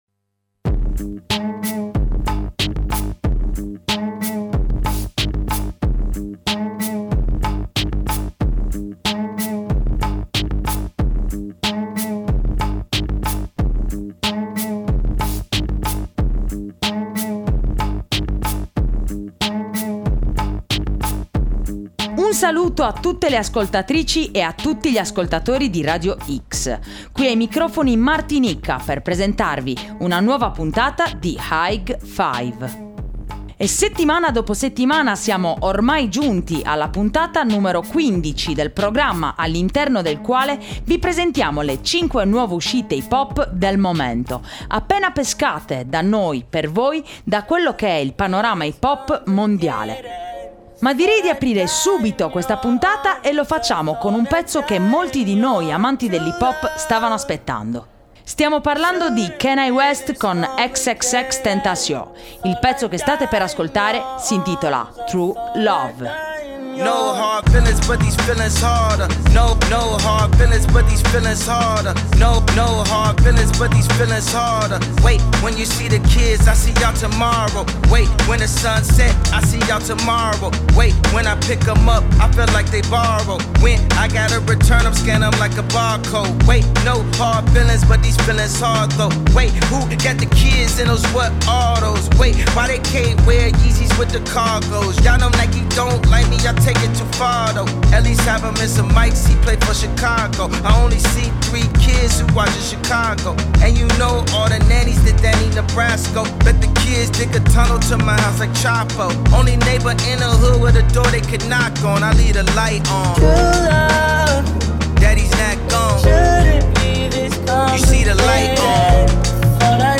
hip hop / rap / trap mainstream mondiale